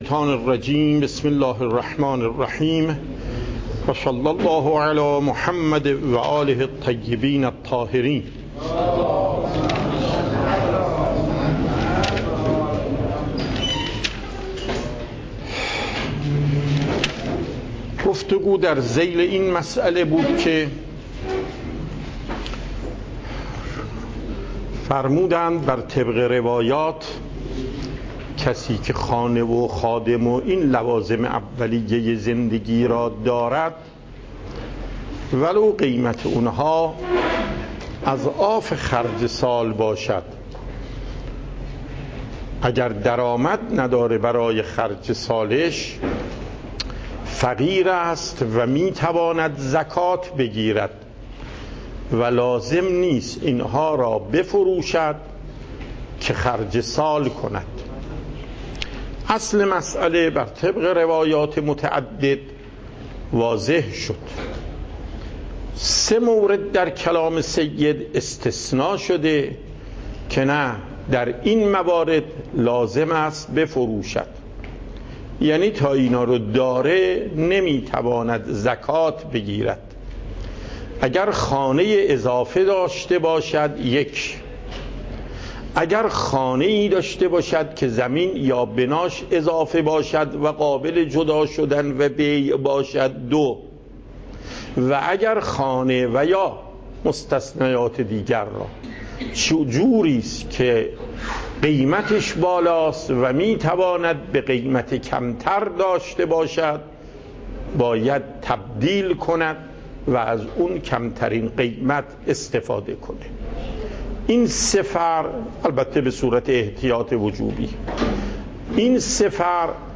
دروس فقه آیت الله محقق داماد